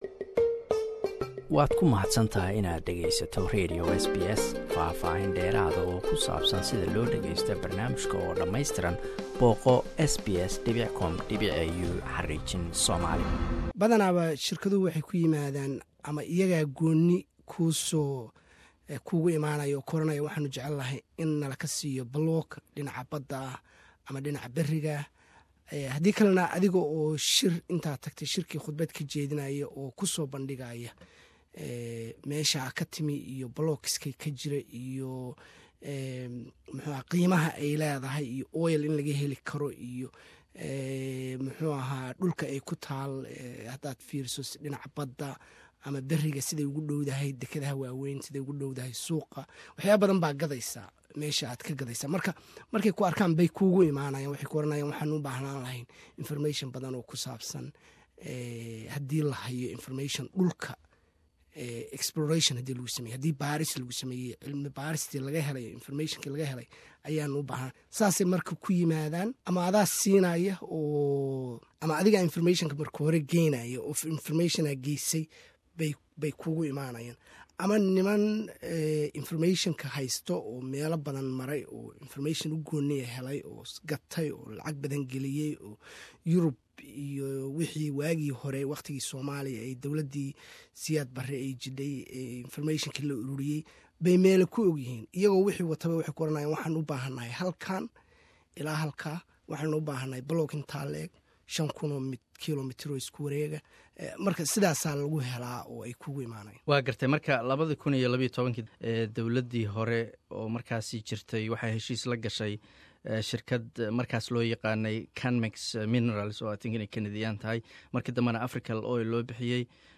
Waraysi: Agaasimihii hore ee hayda macdanta iyo batroolka Puntland Q 1aad.